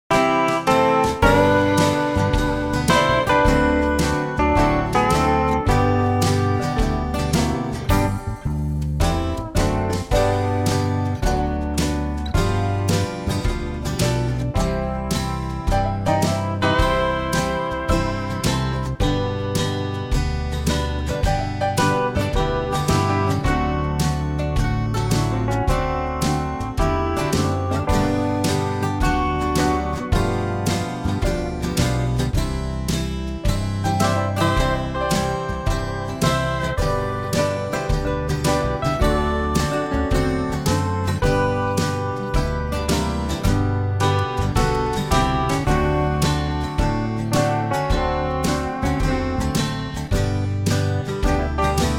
Unique Backing Tracks
key - G - vocal range - D to E
superb country arrangement